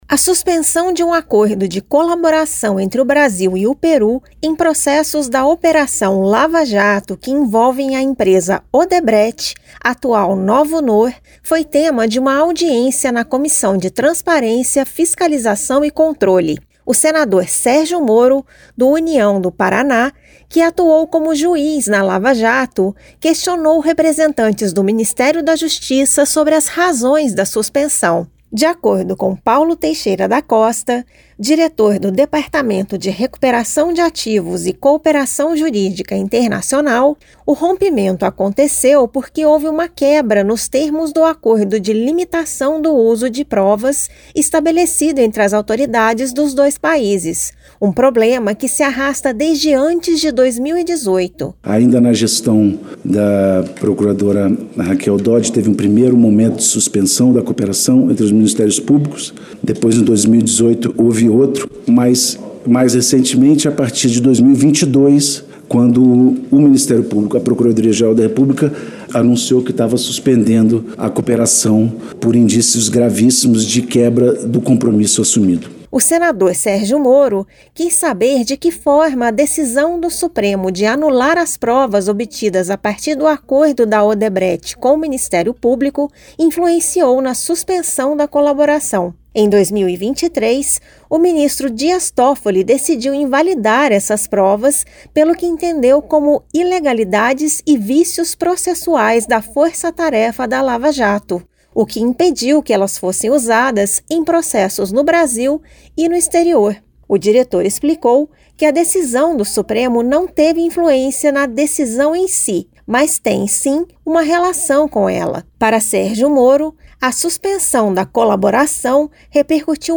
A suspensão de acordo de colaboração entre o Brasil e o Peru em processos da operação Lava-Jato que envolvem a empresa Odebrecht, atual Novonor, foi tema de audiência na Comissão de Transparência, Fiscalização e Controle. O senador Sérgio Moro (União-PR) indagou representantes do Ministério da Justiça sobre as razões da suspensão.